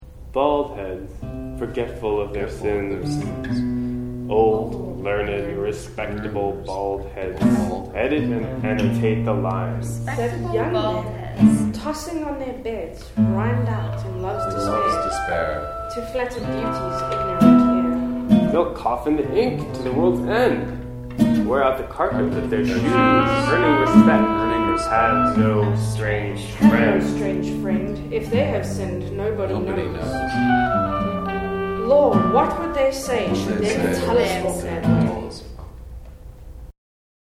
All sound recorded by Parallel Octave on Sunday, September 9, 2012, in the Arellano Theater on the JHU campus.